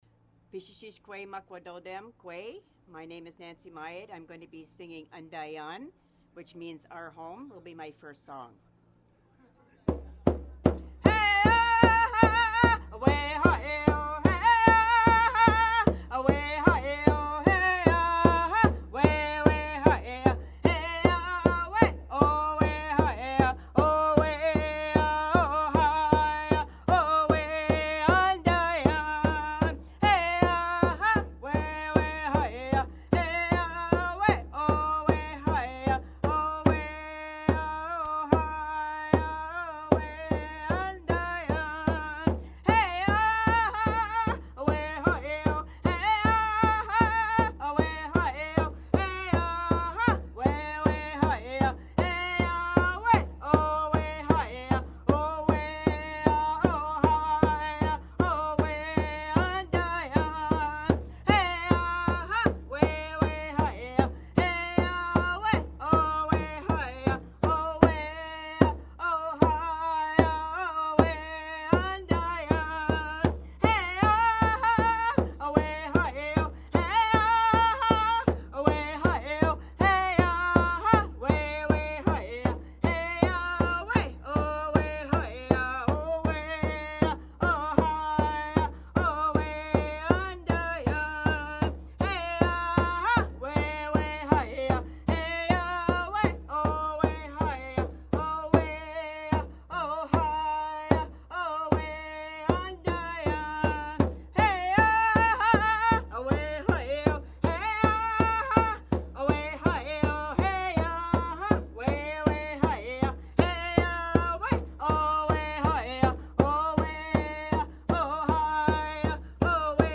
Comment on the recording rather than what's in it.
performs live